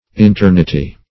Internity \In*ter"ni*ty\, n. State of being within; interiority.